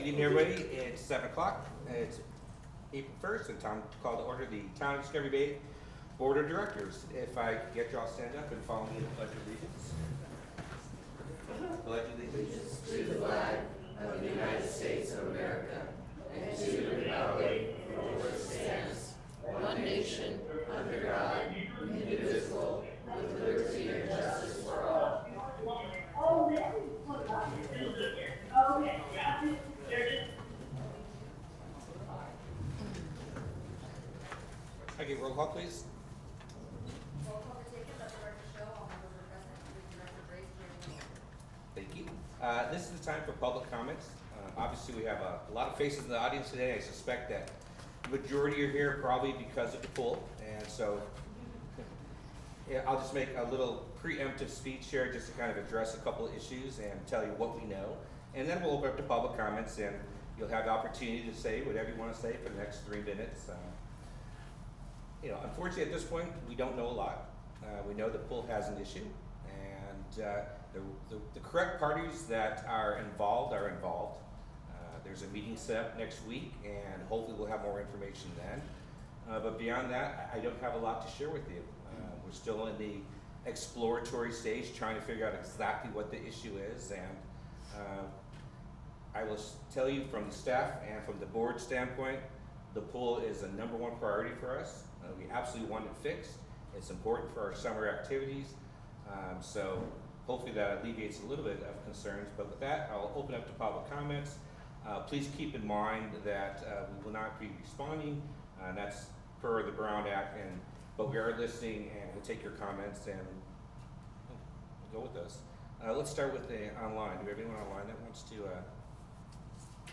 The Town of Discovery Bay CSD meets twice monthly on the first and third Wednesday of each month at 7:00 p.m. at the Community Center located at 1601…